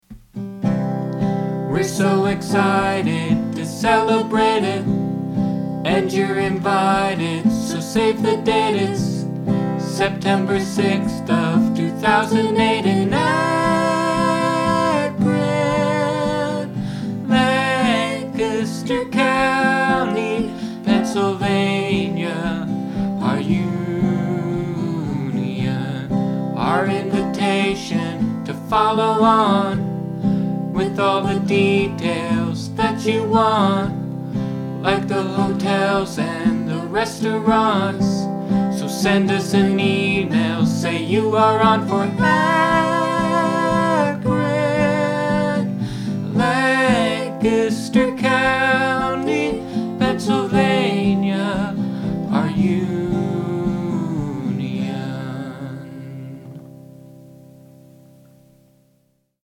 C, Am, C, Am, C, Am, C, G7, Am, F, C, G7, C
verse, chorus, verse, chorus